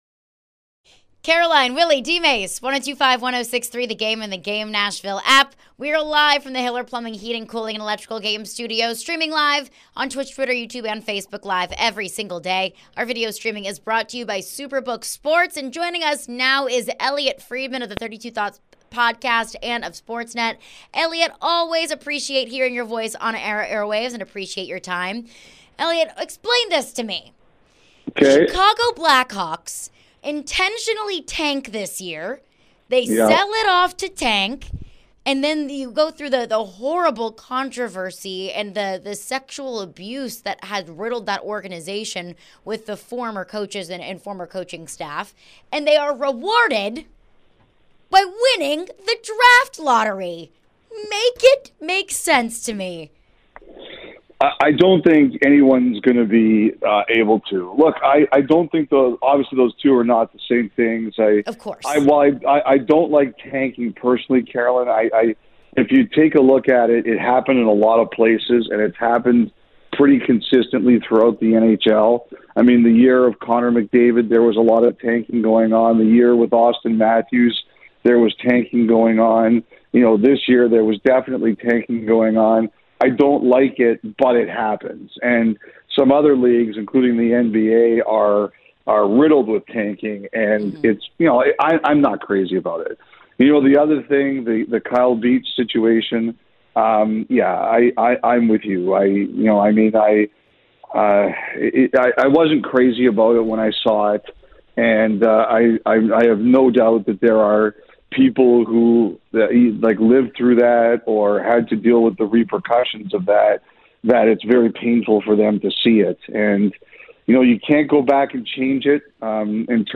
Elliotte Friedman Interview (5-11-23)